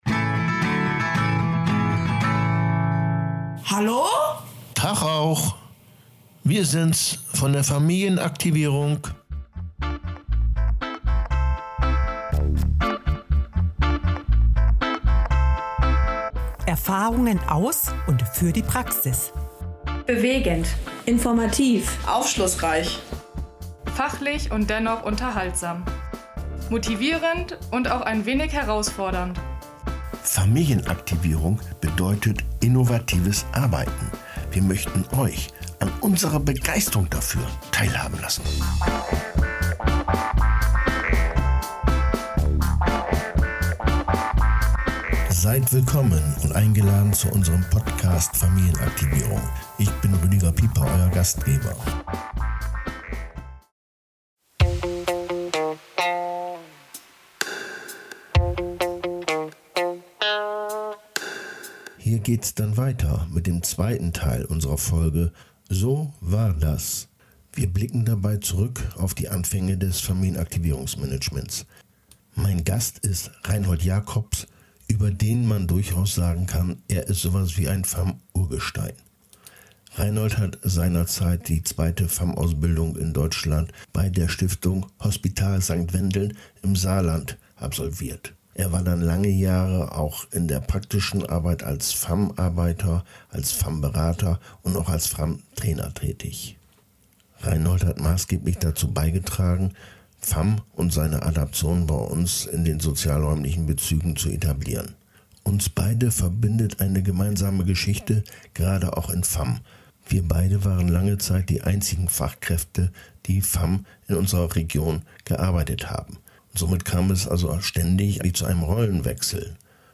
Ein Gespräch von Zweien, die von Beginn an dabei waren.